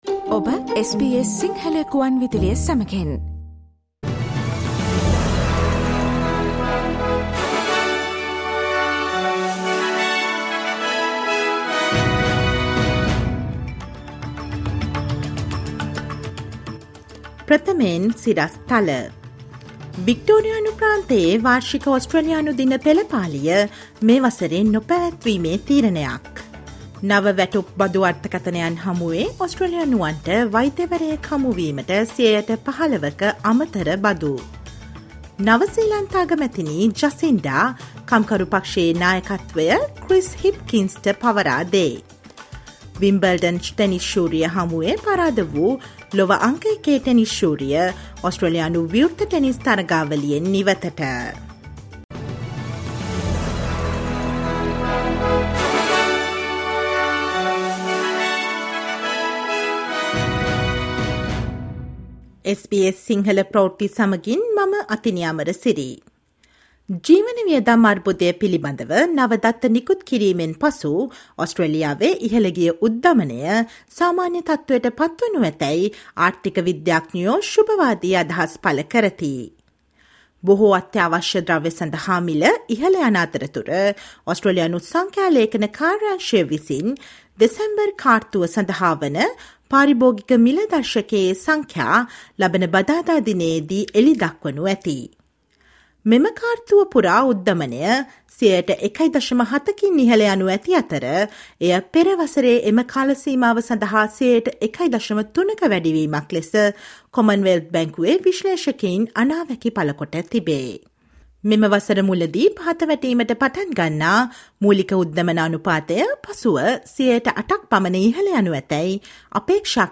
Australia day is a day of mourning, Victoria stops its annual parade: SBS Sinhala news on 23 Jan